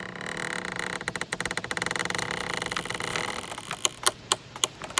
coffin.ogg